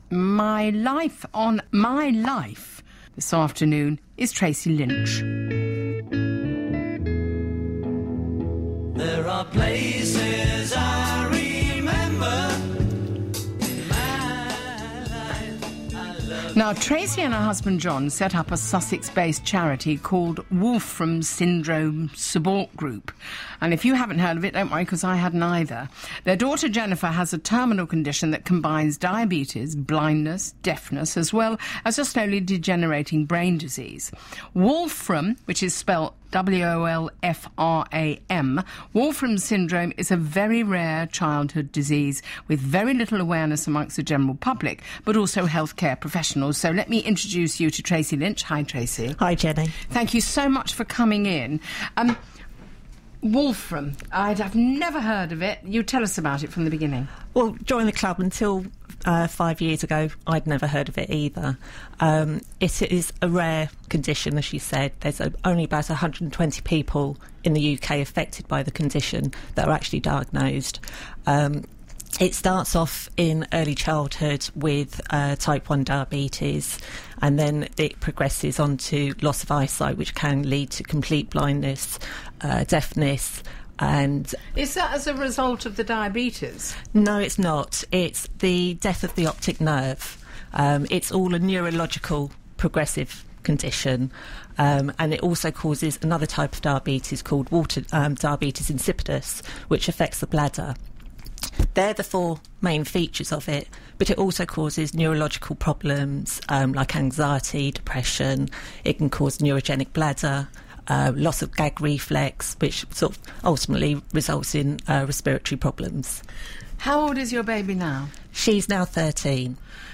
Live on BBC Sussex on the 2nd September.